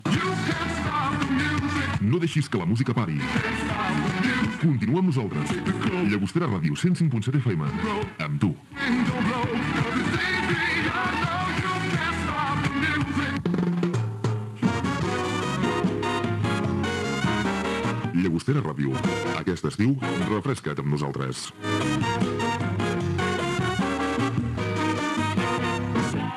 Indicatiu d'estiu